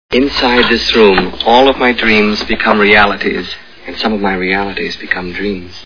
Willy Wonka and the Chocolate Factory Movie Sound Bites